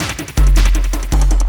53 LOOP 02-R.wav